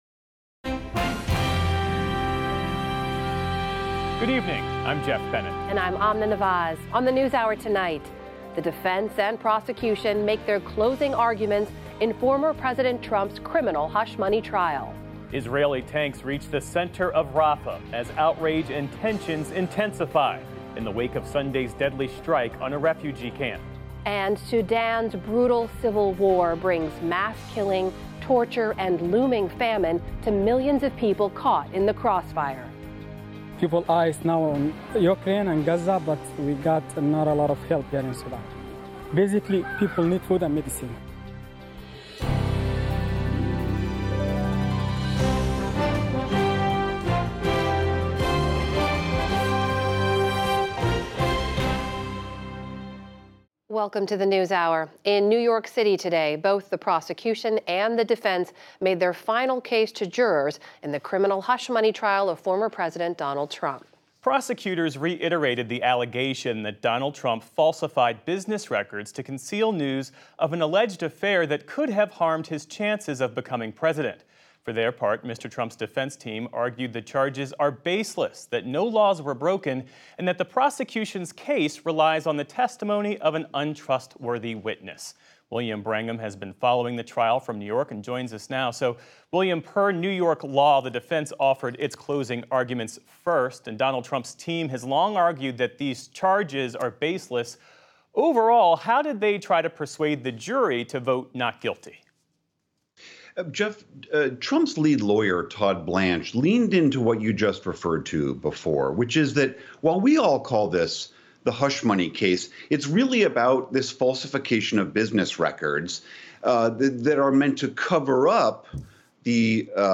PBS NewsHour News, Daily News